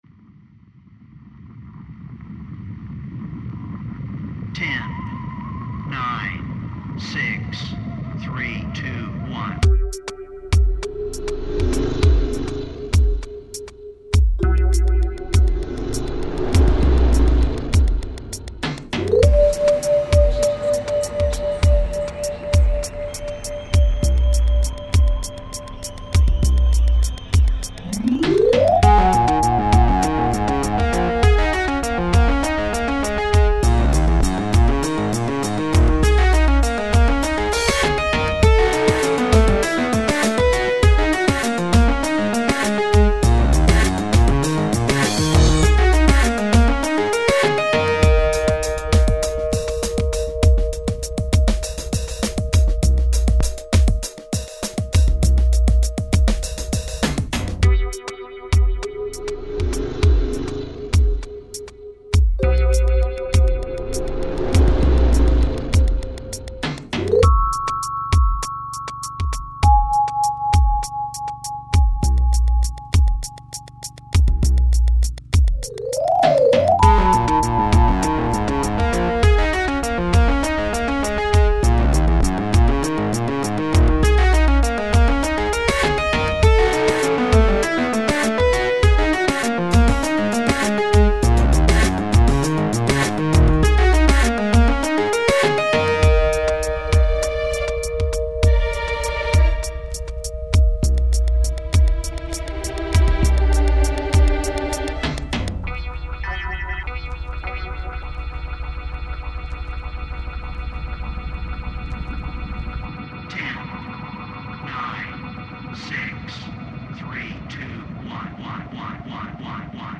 —Zoomin' sounds!
—Ethereal tones!
—Beats that make you bounce!